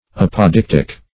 Apodictic \Ap`o*dic"tic\, a.